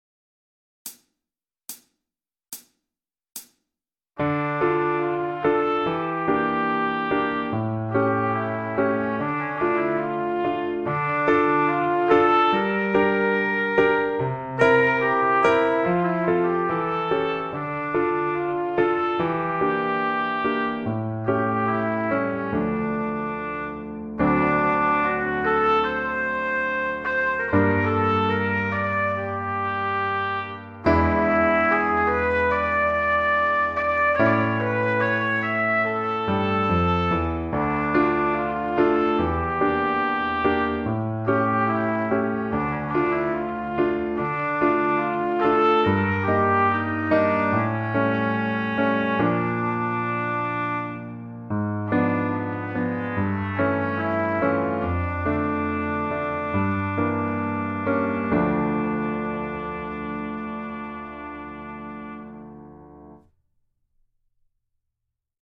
lecture chantée - complet